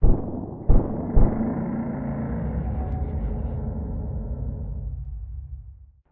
Commotion23.ogg